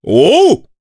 Gau-Vox_Happy4_jp.wav